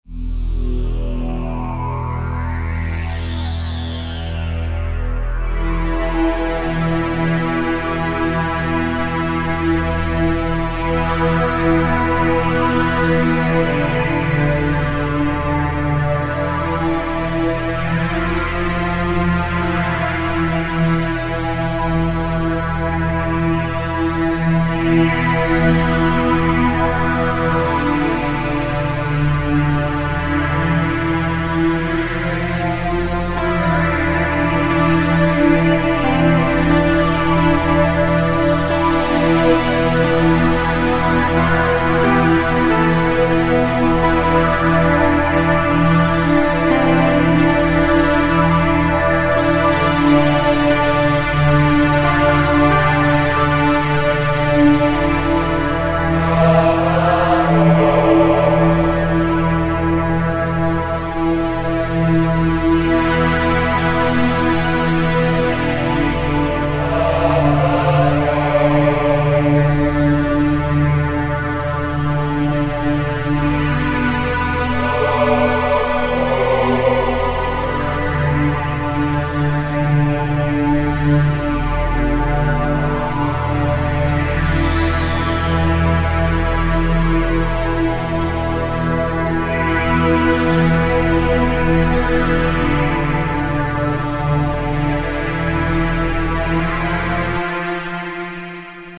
It sounds too serious IMO.
It's not very ice/snow-like.
An interesting piece all right.